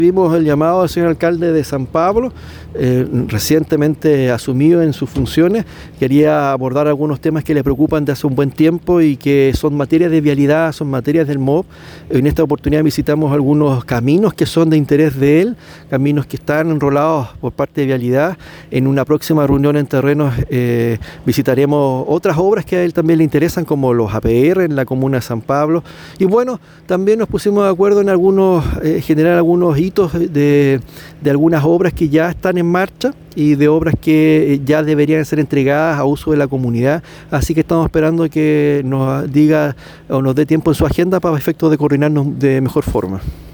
Por su parte, el Seremi Juan Fernando Alvarado expresó su disposición para colaborar con la nueva administración y destacó la importancia de coordinar esfuerzos para avanzar en estas iniciativas prioritarias.